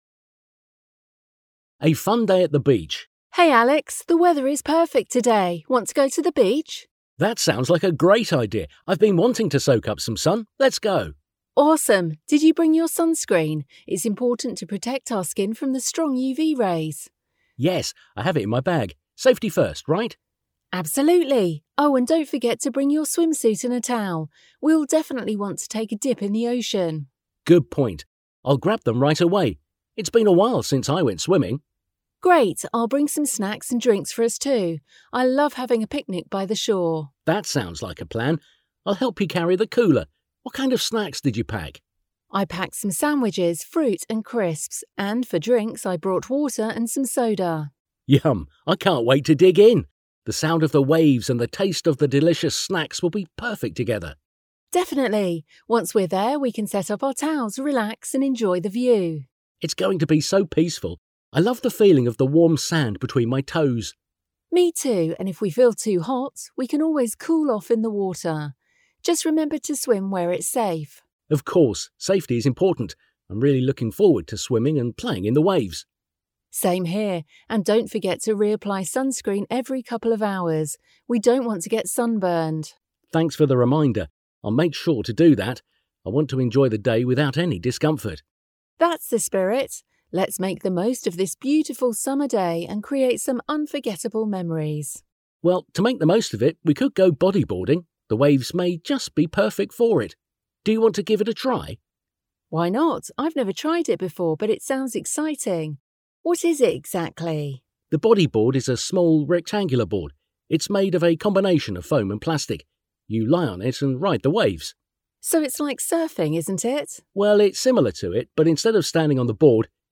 Fill in the gaps with information from the dialogue.